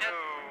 Neptunes Two SFX.wav